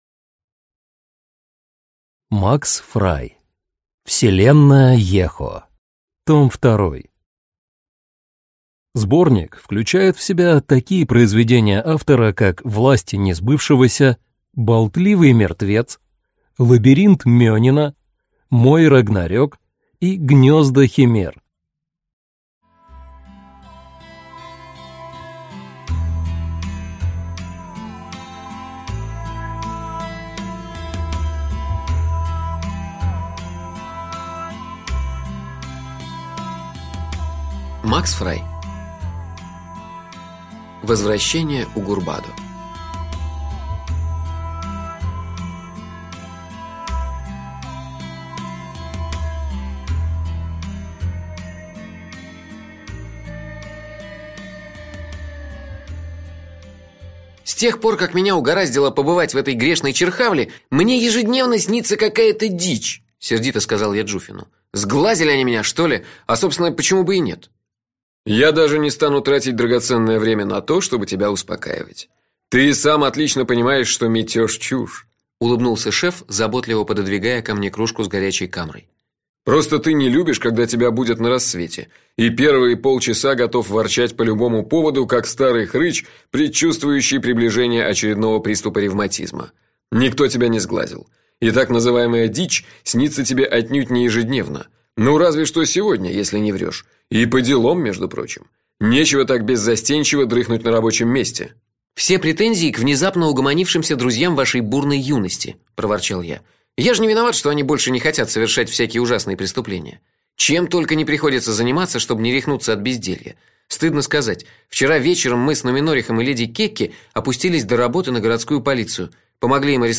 Аудиокнига Вселенная Ехо. Том 2 | Библиотека аудиокниг